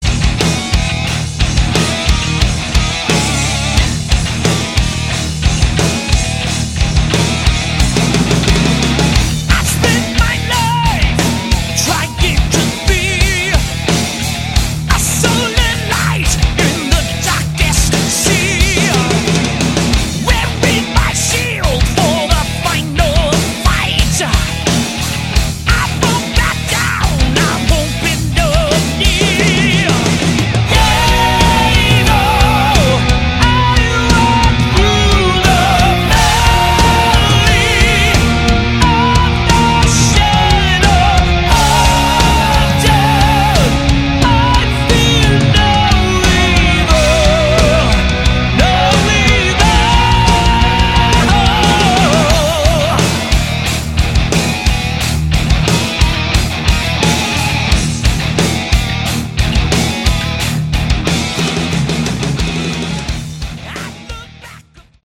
Category: Hard Rock
lead vocals, guitar
drums, background vocals